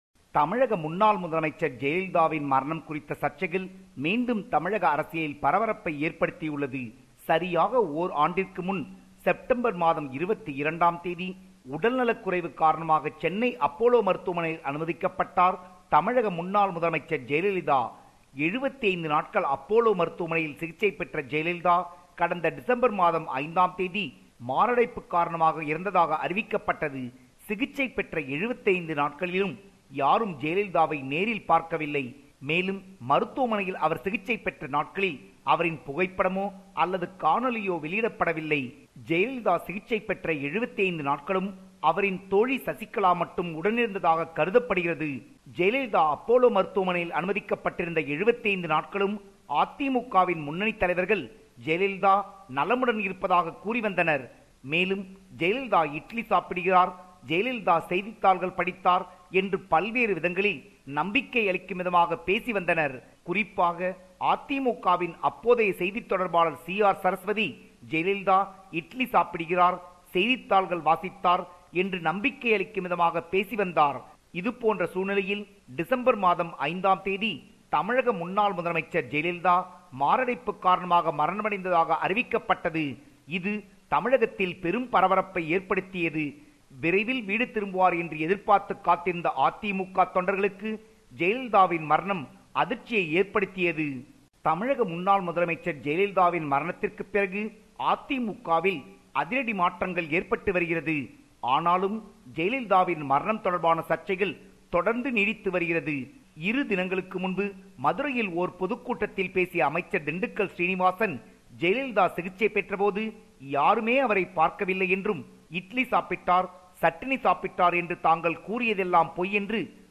compiled this report.